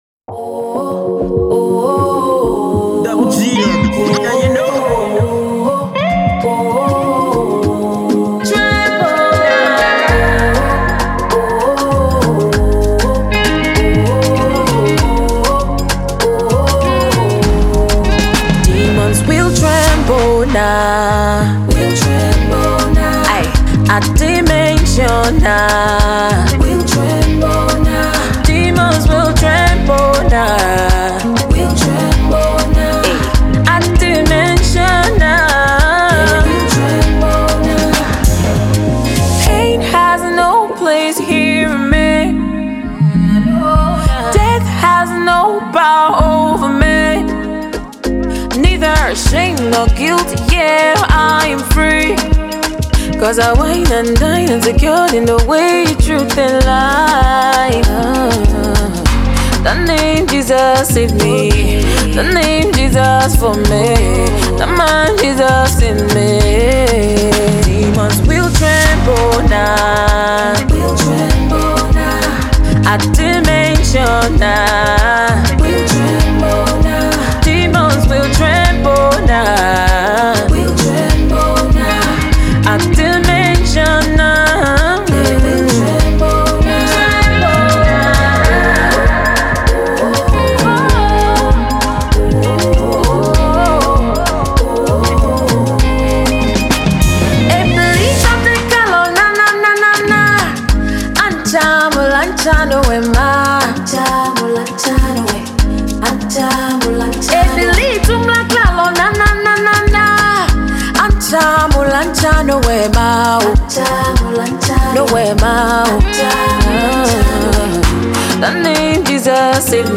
Conscious and Inspirational.